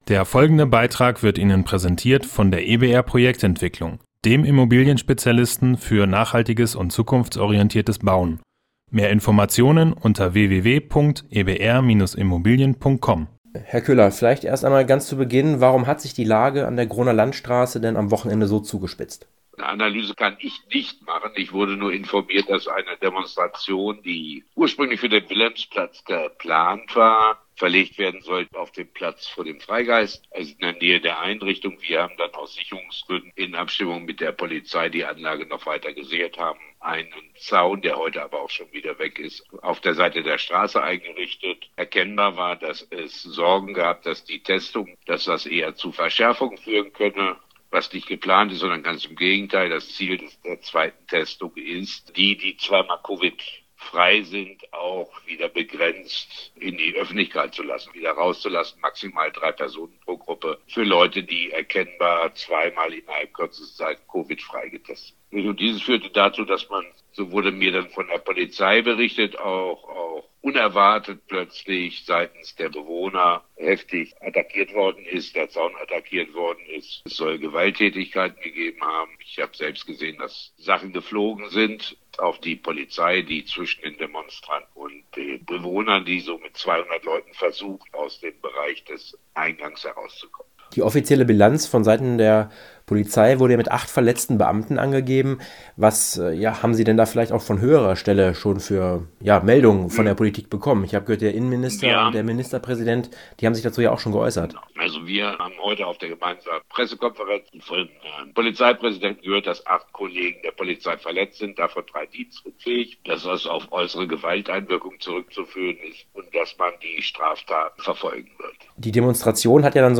Oberbürgermeister Köhler hat mit